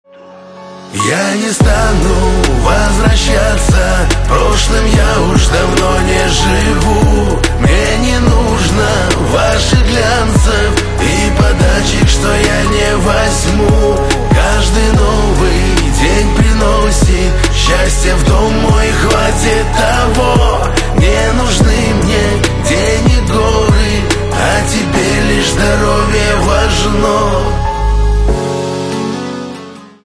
русский рэп
басы